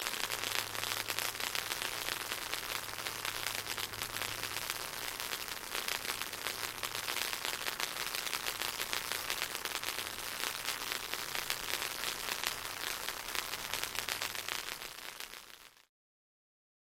Звуки сварочного аппарата
Тихий гул сварки или возможно это электрическая дуга